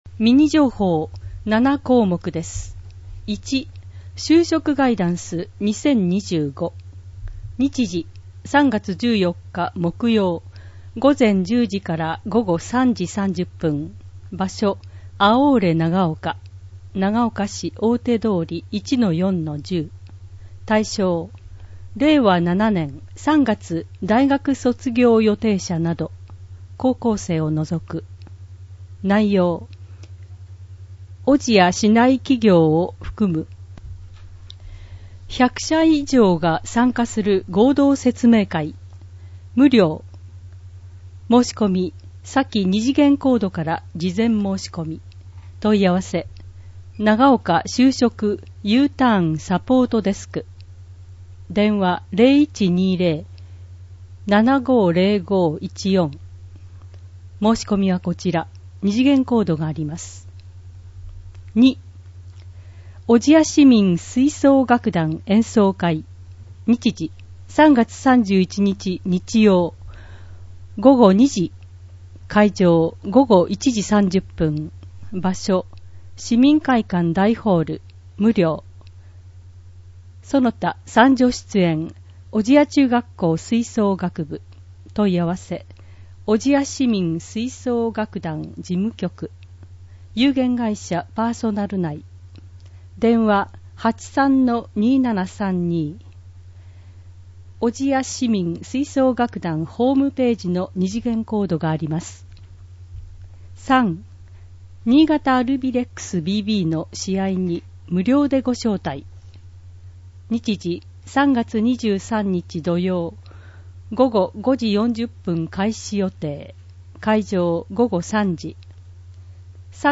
視覚障がいのある方や高齢で広報誌を読むことが難しい方などのために、ボランティアサークル「小千谷市音声訳の会」のみなさんが音訳した音声版（MP3）の広報おぢやを配信します。